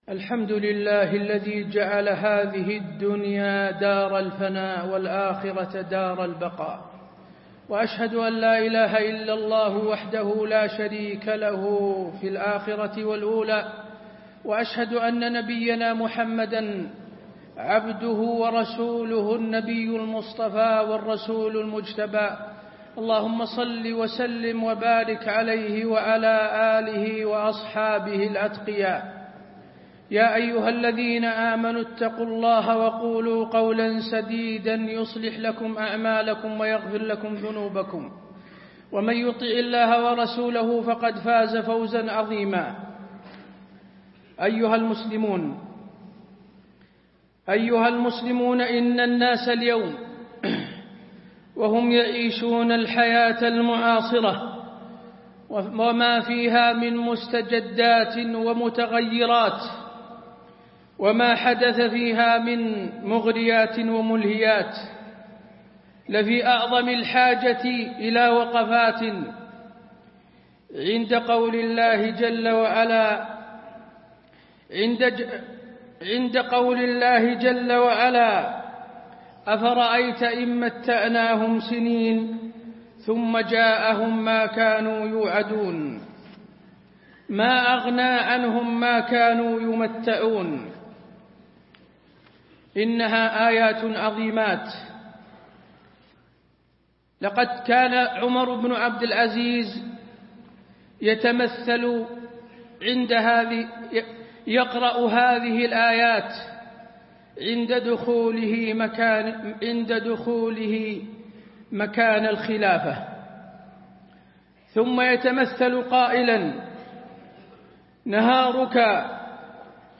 تاريخ النشر ٤ جمادى الآخرة ١٤٣٥ هـ المكان: المسجد النبوي الشيخ: فضيلة الشيخ د. حسين بن عبدالعزيز آل الشيخ فضيلة الشيخ د. حسين بن عبدالعزيز آل الشيخ حقيقة الدنيا